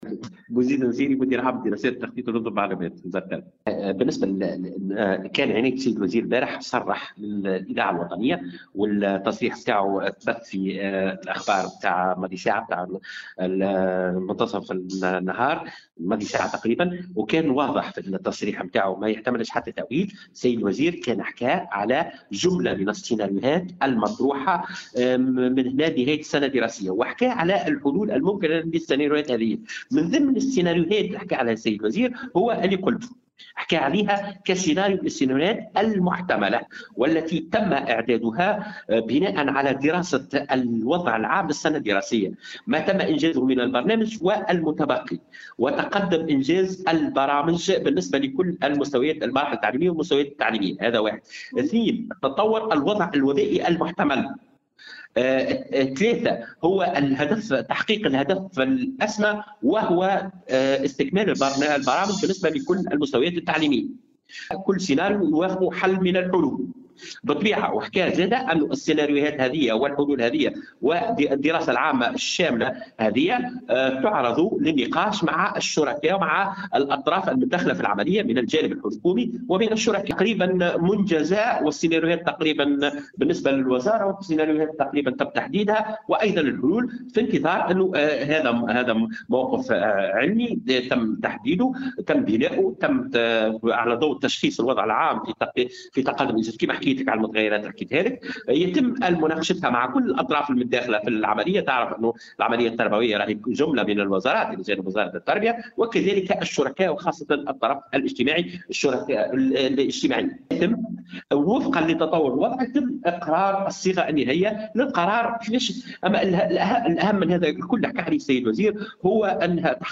S’exprimant au micro de Tunisie Numérique